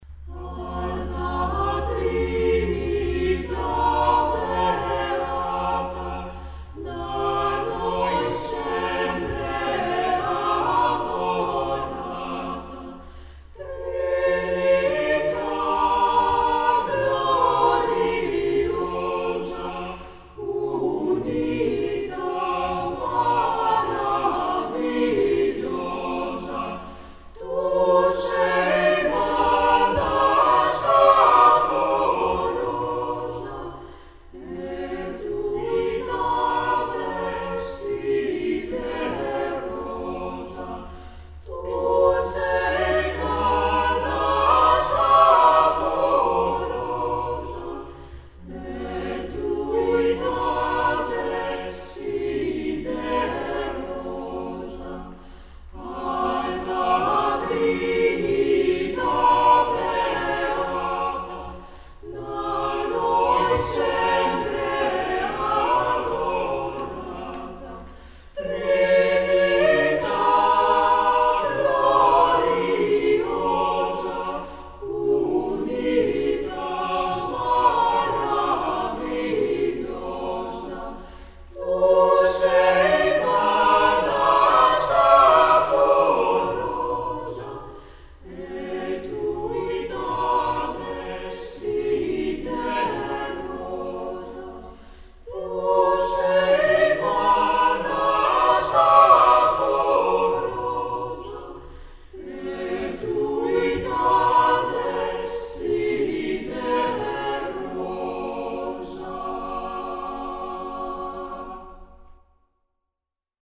Ηχογράφηση από την πρόβα της Τρίτης 2 Μαΐου 2006.
Με ισοστάθμιση, αντήχηση και στερεοφωνία
eq = Equalizer, rev = reverb